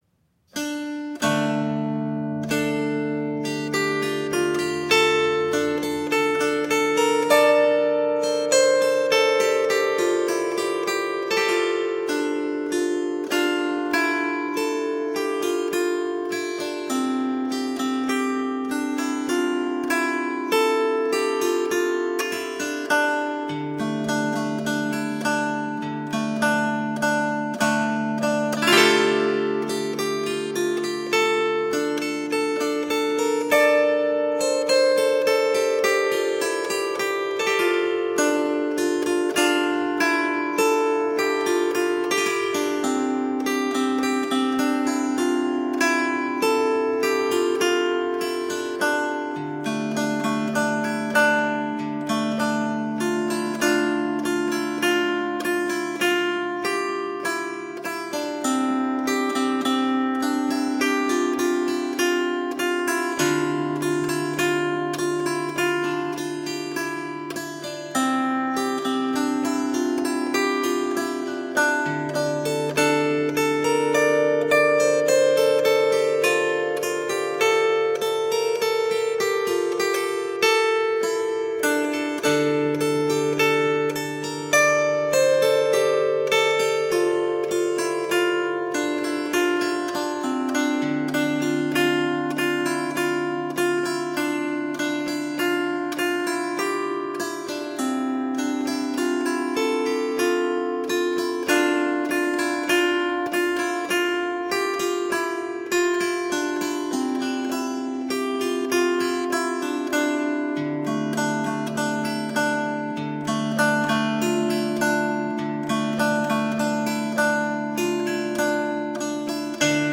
Medieval and middle eastern music.